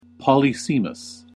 /pəˈlɪs.ɪ.məs(米国英語)/